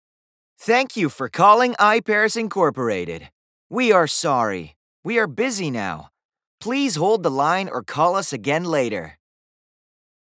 キャラクターボイスに特に情熱を持ち、活気に満ちた声と幅広い音域を活かして、 ビデオゲーム、アニメ、オーディオドラマなどで様々なキャラクターに命を吹き込んでいる。
ナレーターランクⅢ（男性）